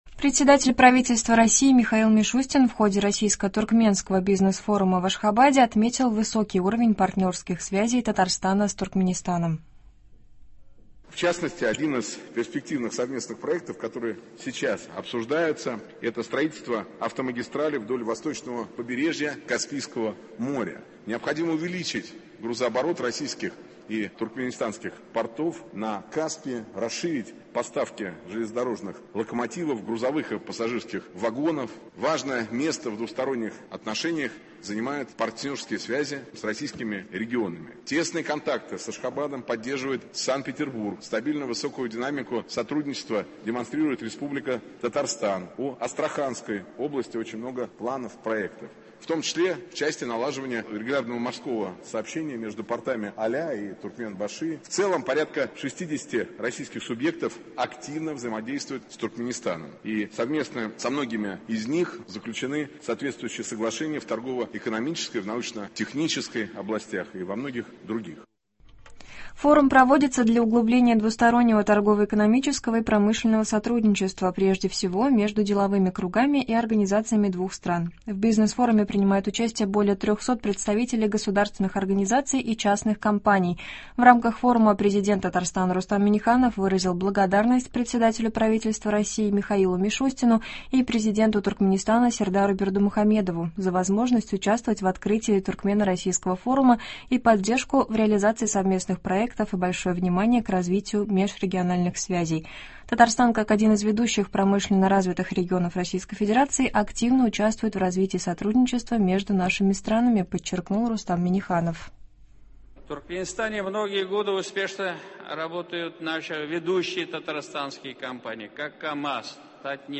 Новости (20.01.23)